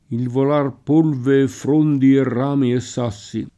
fr1nde] (col pl. -di): Subito vidi quella altera fronde [S2bito v&di kU%lla alt$ra fr1nde] (Petrarca); Il volar polve e frondi e rami e sassi [